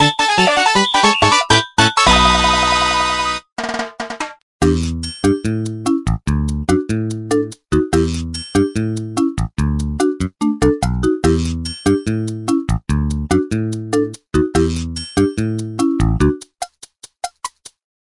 VS. Battle victory theme